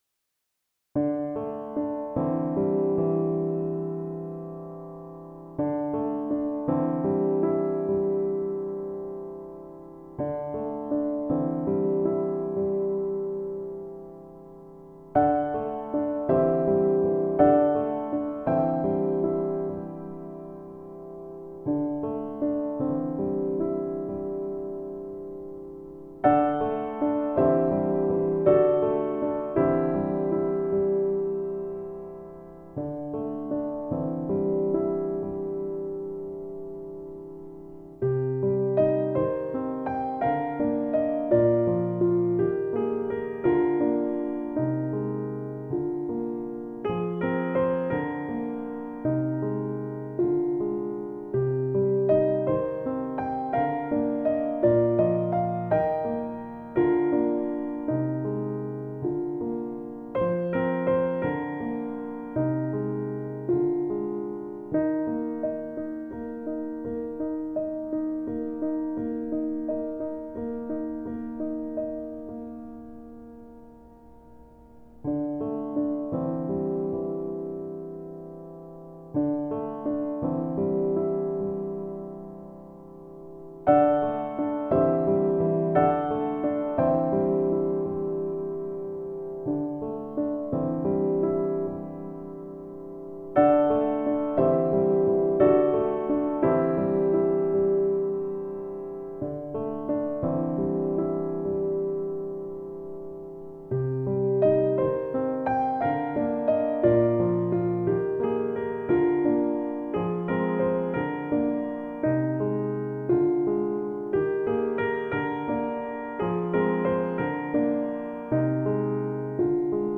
時にシンプルで 時に複雑なひびき 重なりあう音色の変化 ジャジーなテンション 人間らしいアナログな間